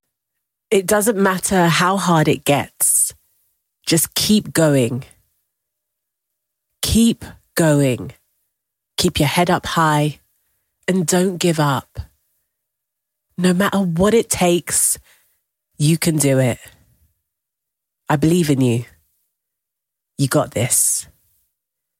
Female
Natural Speak
Natural London Accent
All our voice actors have professional broadcast quality recording studios.